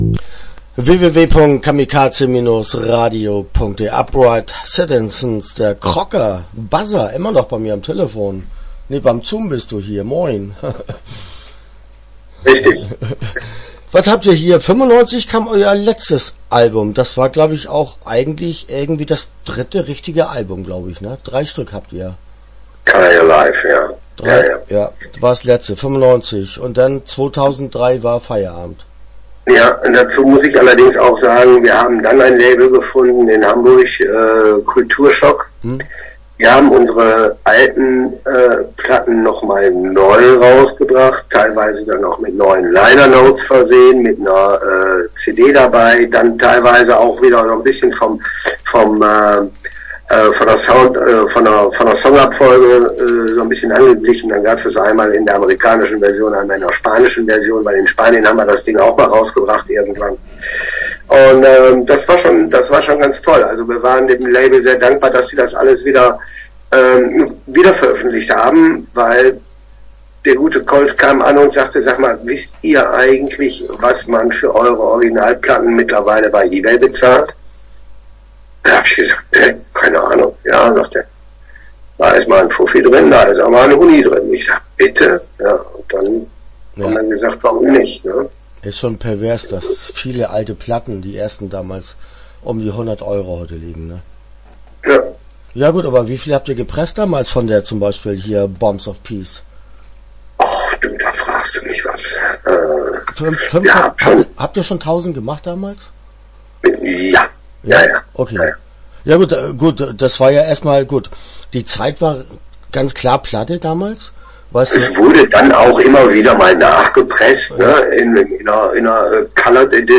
Upright Citizens - Interview Teil 1 (12:33)